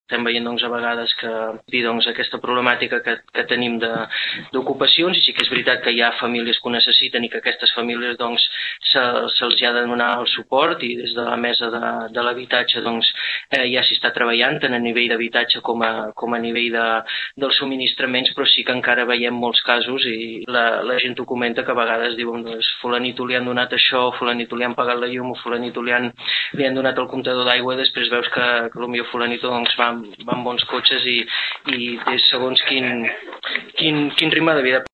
El Ple de l’Ajuntament de Tordera va aprovar per unanimitat en la sessió ordinària d’ahir una moció presentada a instàncies de la Plataforma d’Afectats per la Hipoteca de Tordera pronunciant-se a favor de mesures urgents per combatre els desnonaments i la pobresa energètica.
El regidor del PP, Xavier Martin va matisar que cal un control més estricte en l’aplicació d’aquestes propostes.